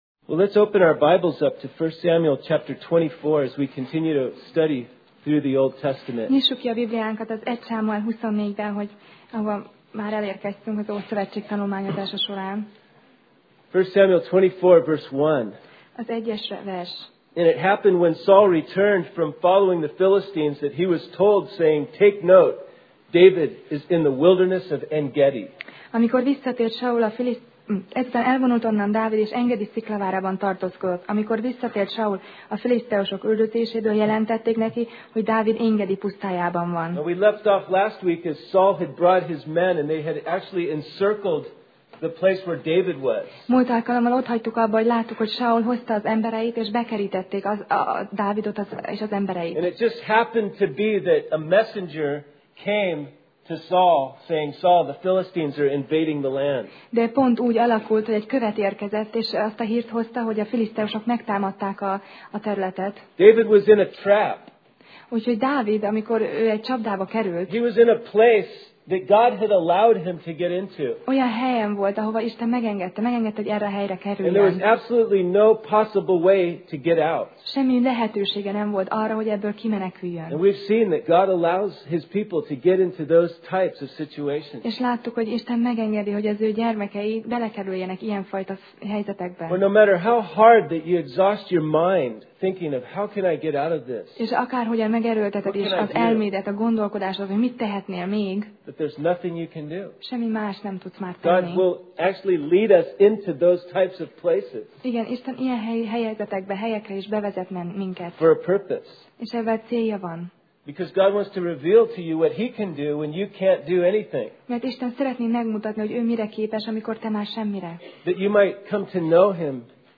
Alkalom: Szerda Este